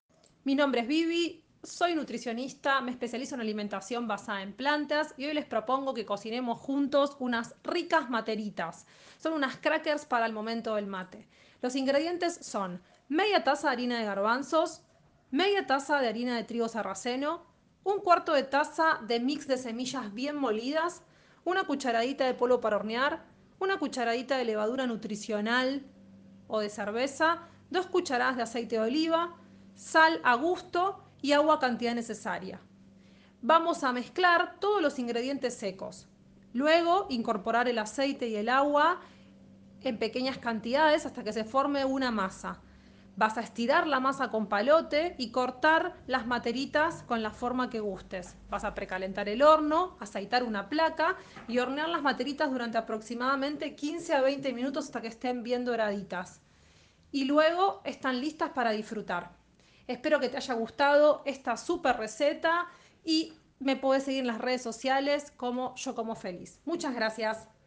Audio receta de las «materitas»